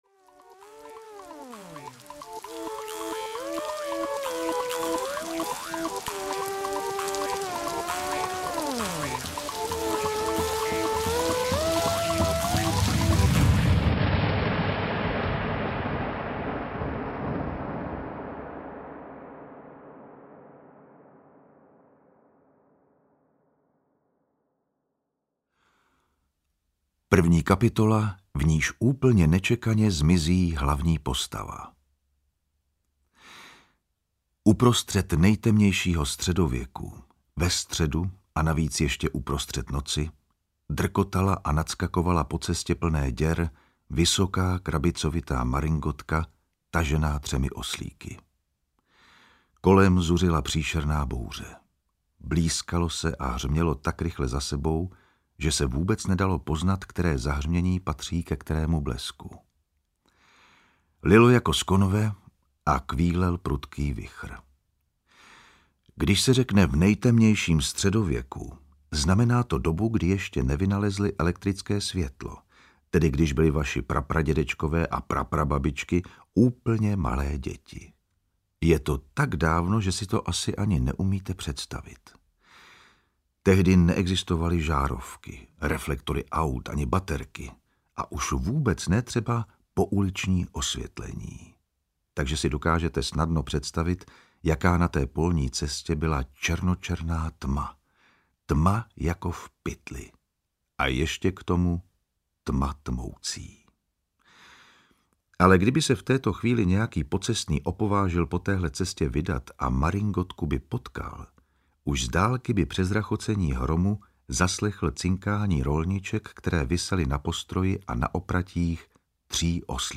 Rodrigo Raubíř a neohrožený Špunt audiokniha
Ukázka z knihy
• InterpretJan Vondráček